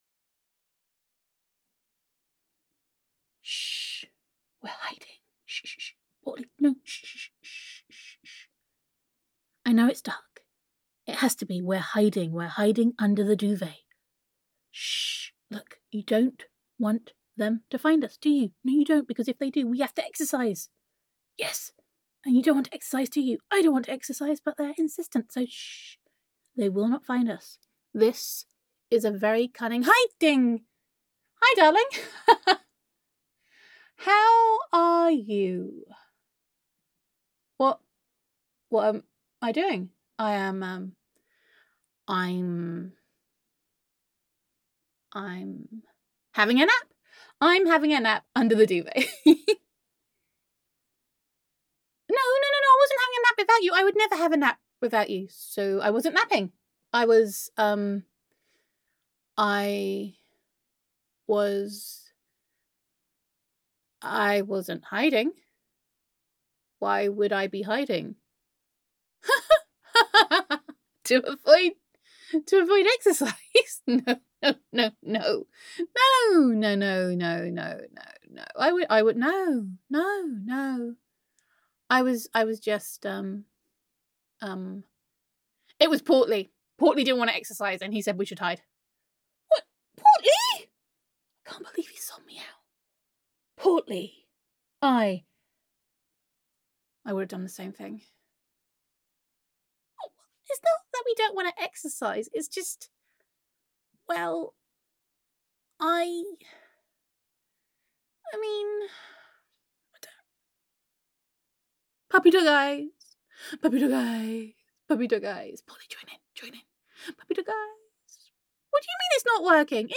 [F4A] Day Four - Hiding It Out [Home With Honey][Girlfriend Roleplay][Self Quarantine][Domestic Bliss][Gender Neutral][Self-Quarantine With Honey]